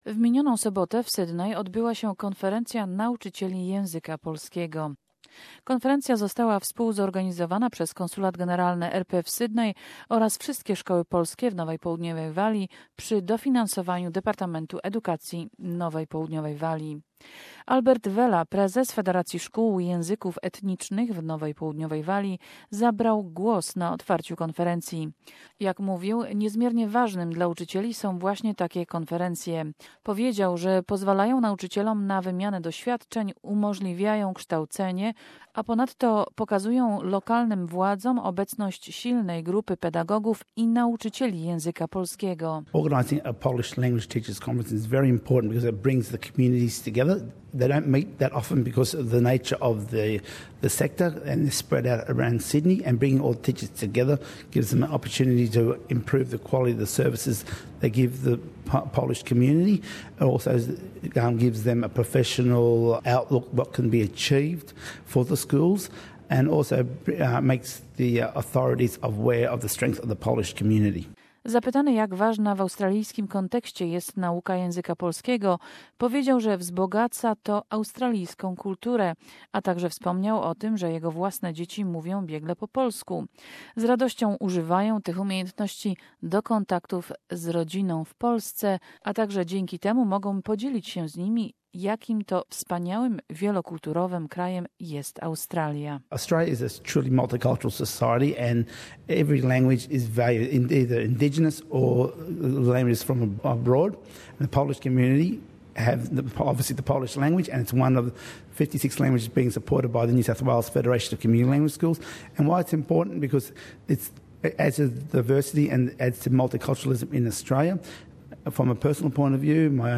Report from the conference which was held on Saturday 3 June in Sydney.